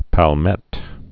(păl-mĕt)